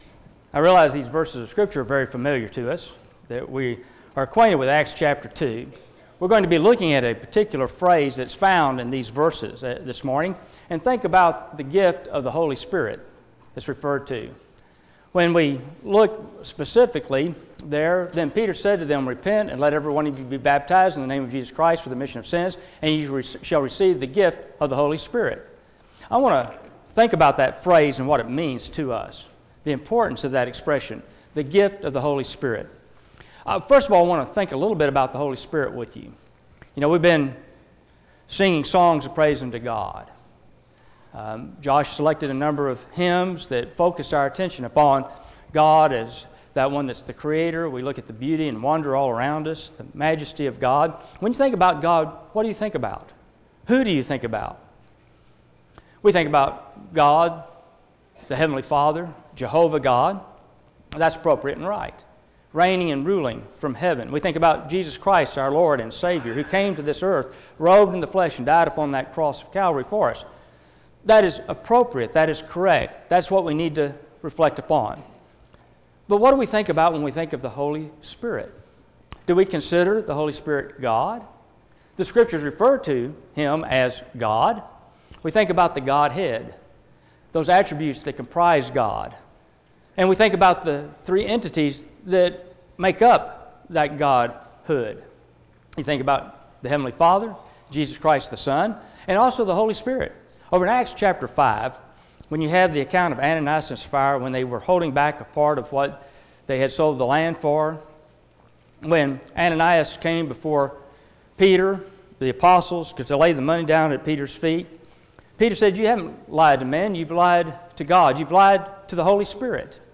Acts 2:36-42 Service Type: Sunday AM Topics